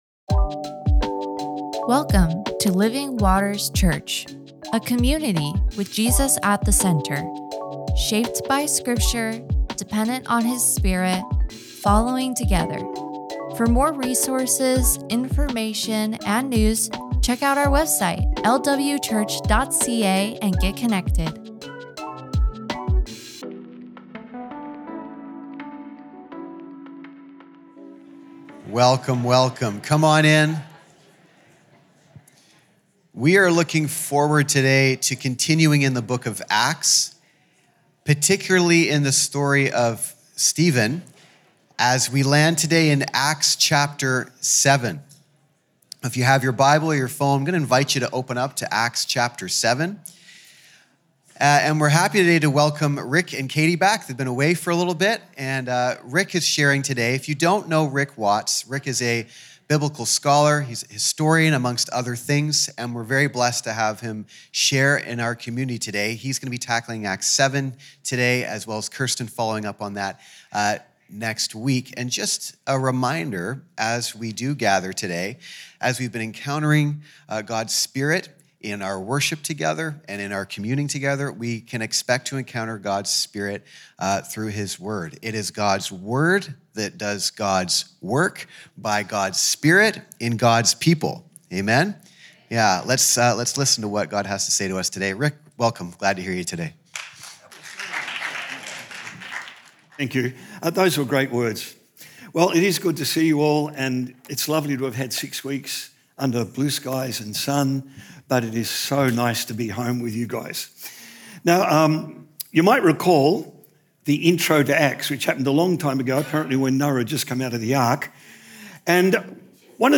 Sermons | Living Waters Church